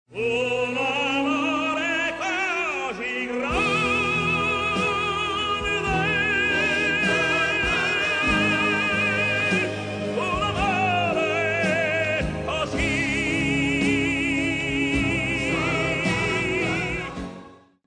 Come si vede, questa frase ha un culmine espressivo nei due MI della seconda battuta (indicati dall'asterisco), corrispondenti alle sillabe "gran-de". E' qui che la voce si fa più intensa, come possiamo ascoltare: